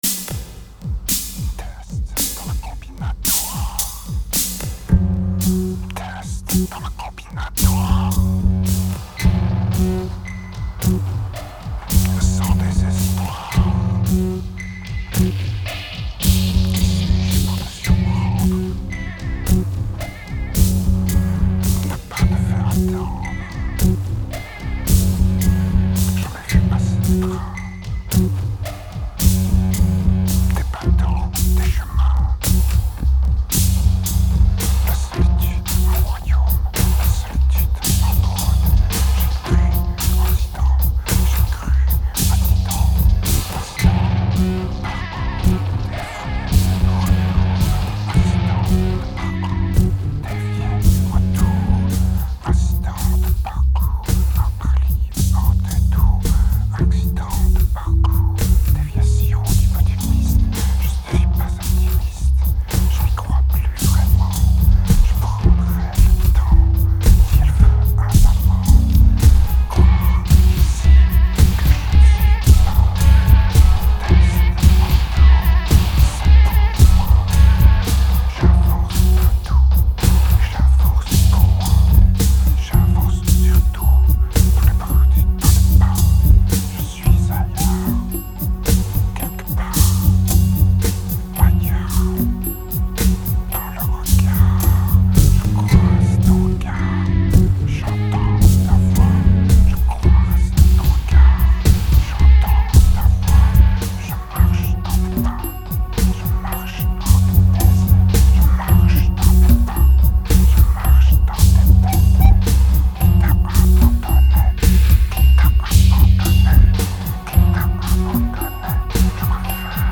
2308📈 - -52%🤔 - 94BPM🔊 - 2008-10-17📅 - -361🌟